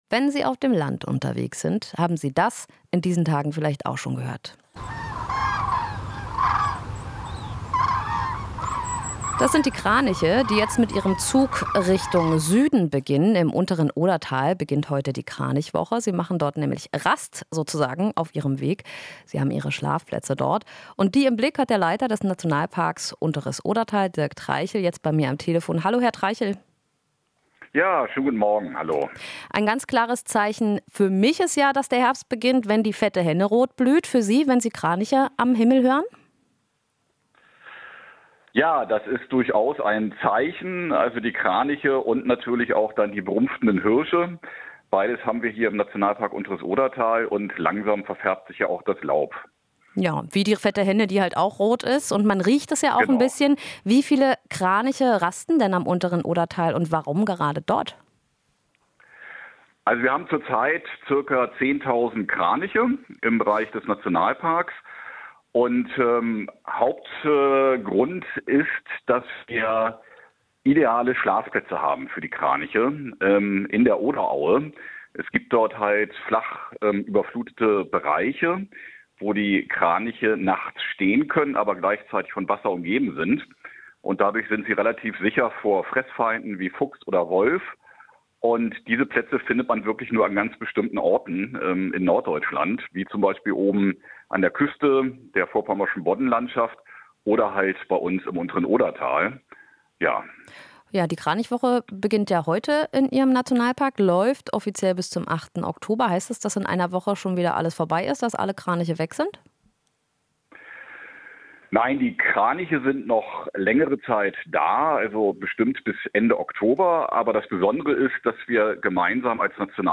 Interview - Kranichwoche beginnt - "Ideale Schlafplätze in der Oderaue"